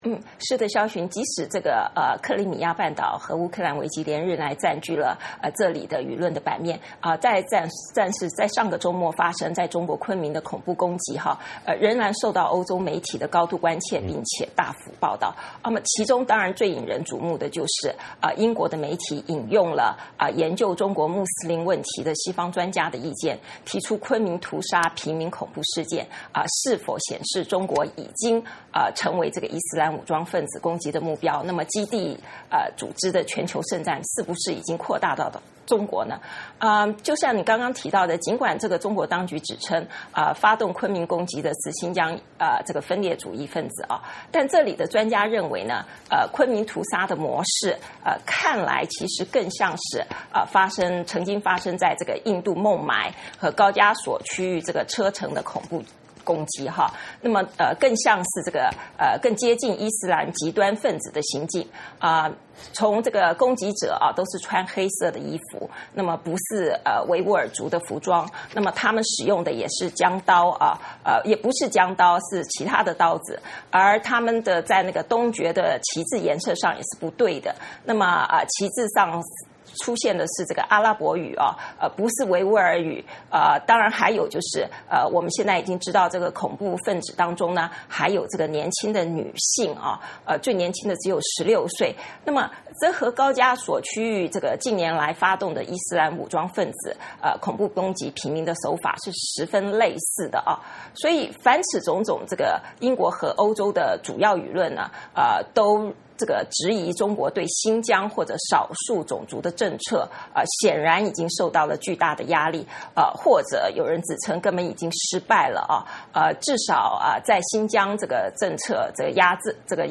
VOA连线：欧洲关注昆明恐怖攻击事件